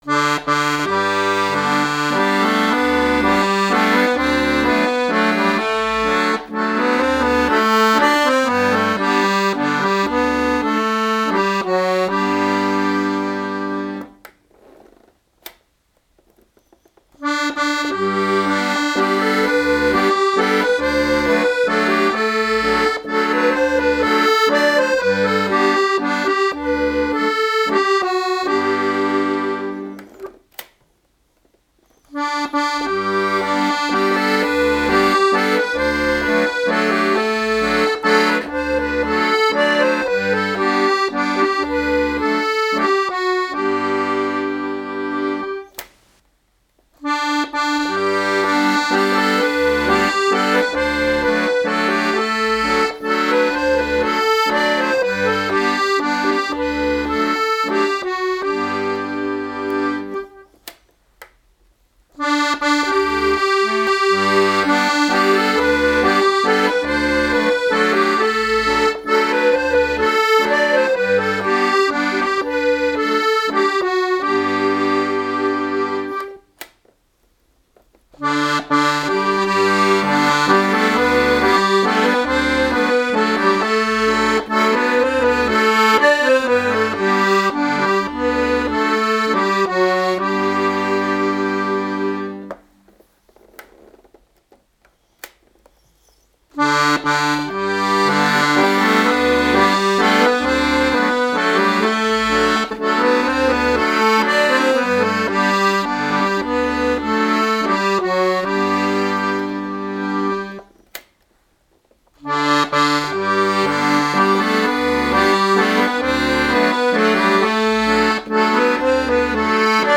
Musetteakkordeon
Ich habe jetzt einmal eine kurze Volksweise eingespielt, und zwar systematisch durch alle Diskantregister. Das Bassregister habe ich konstant gehalten. Vielleicht vermittelt das die klanglichen Möglichkeiten des Akkordeons.